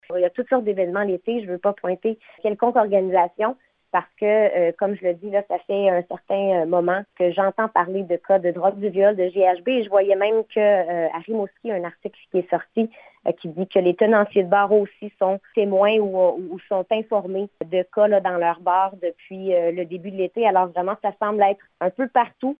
Méganne Perry Mélançon explique que l’été est propice au rassemblement dans les bars ou autres types d’événements et qu’il faut être vigilant.